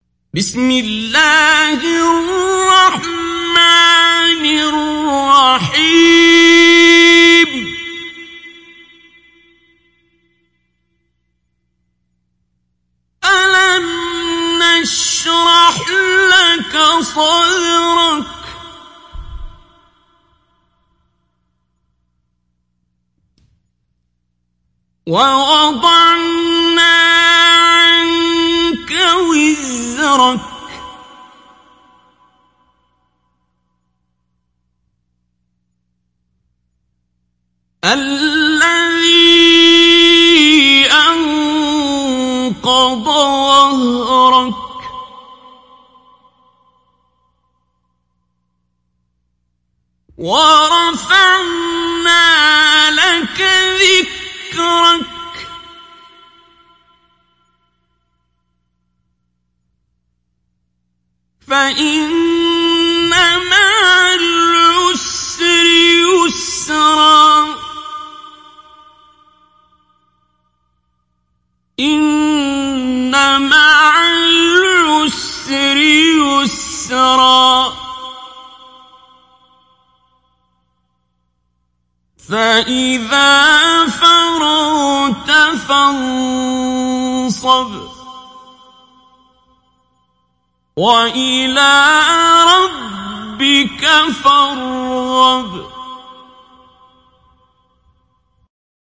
Surah Ash Sharh Download mp3 Abdul Basit Abd Alsamad Mujawwad Riwayat Hafs from Asim, Download Quran and listen mp3 full direct links
Download Surah Ash Sharh Abdul Basit Abd Alsamad Mujawwad